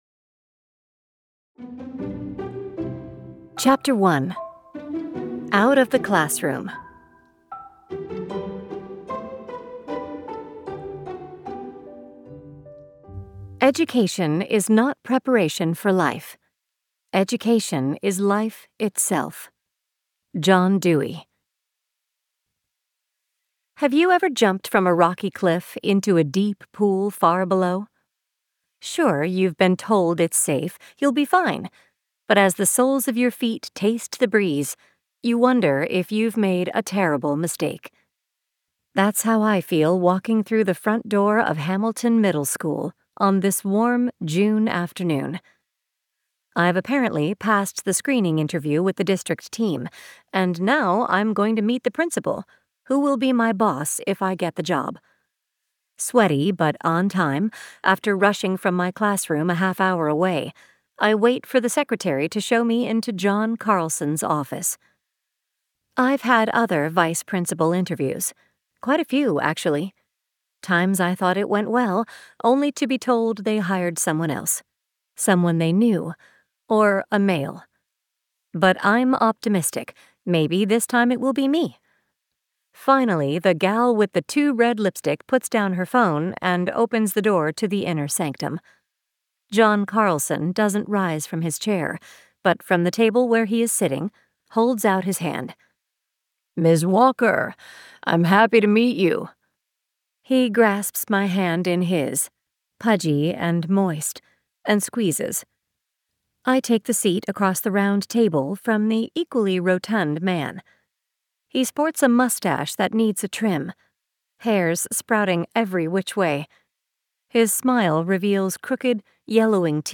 • Audiobook • 8 hrs, 14 mins